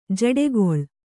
♪ jaḍegoḷ